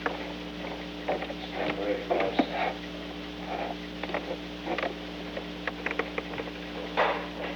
On January 13, 1972, President Richard M. Nixon and Alexander P. Butterfield met in the President's office in the Old Executive Office Building at an unknown time between 3:41 pm and 3:50 pm. The Old Executive Office Building taping system captured this recording, which is known as Conversation 314-003 of the White House Tapes.